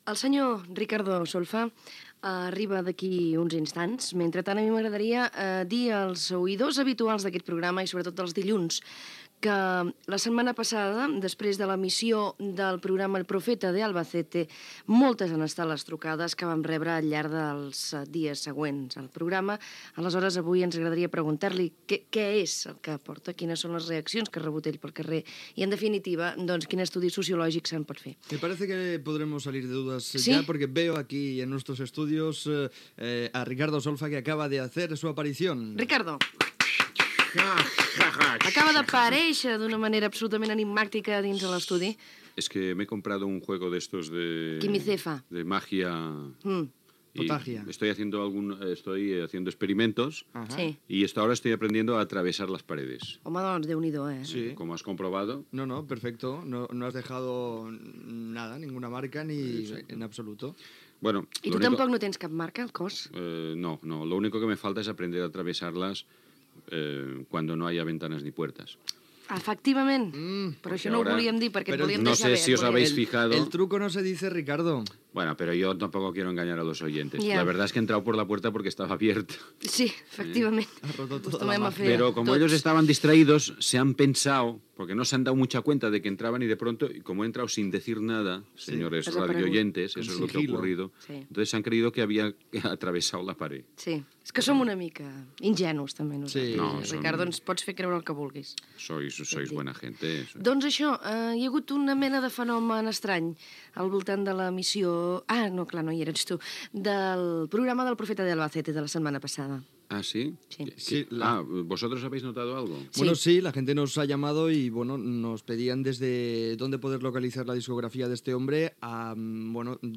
Ricardo Solfa(Jaume Sisa), col·laborador del programa, entra a l'estudi i, després de comentar temes diversos, presenta temes cantats per ell (Ricardo Solfa)
Entreteniment